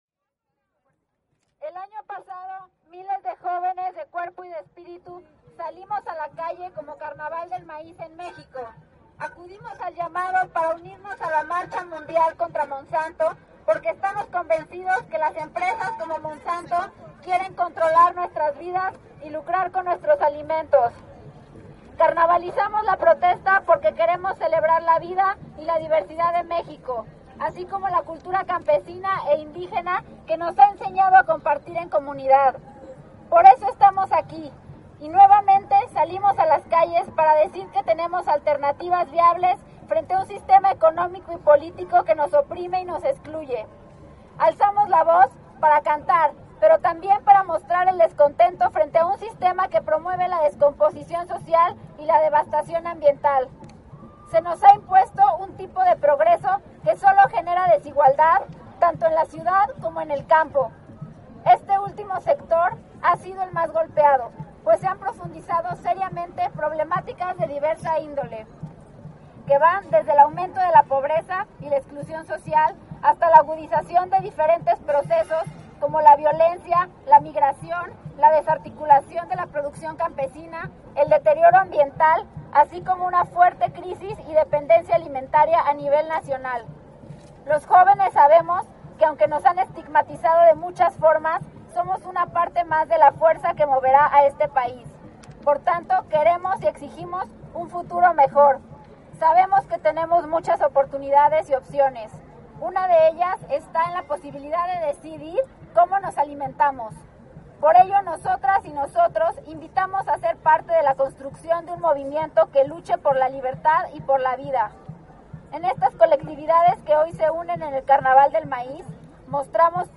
Ya en el monumento a la revolución se leyó un pronunciamiento en el que se dijo que la protesta es para sacar a Monsanto de México, “queremos un campo con maíz y campesinos libres de transgénicos, hacemos de esta protesta un carnaval porque queremos celebrar la vida, alzamos la voz para cantar, pero también para protestar”.
Lectura del Comunicado central